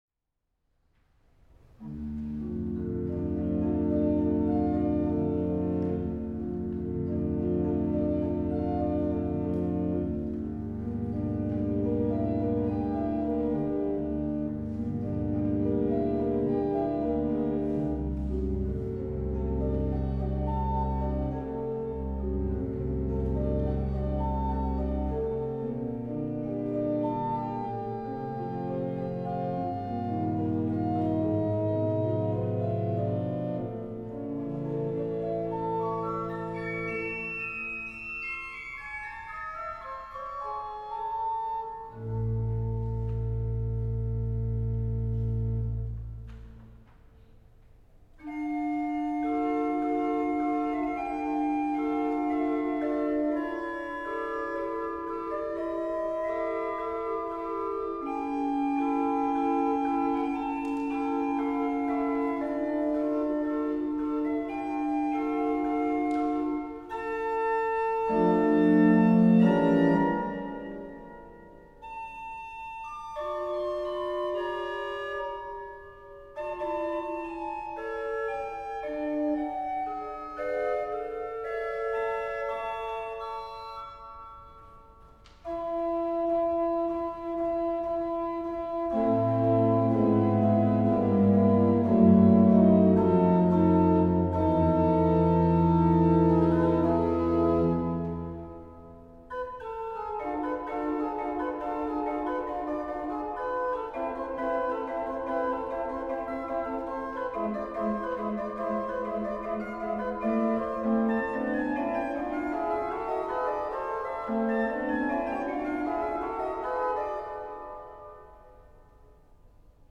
Op deze pagina kunt u een indruk krijgen van de klank van het orgel. U kunt luisteren naar muziek in diverse stijlen, met gevarieerd gebruik van de registers.